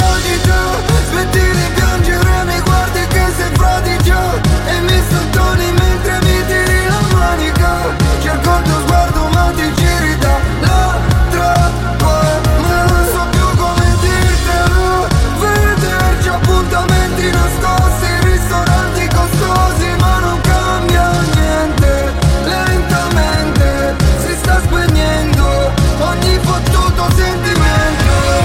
Genere: italiana,sanremo2025,pop.ballads,rap,hit